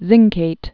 (zĭngkāt)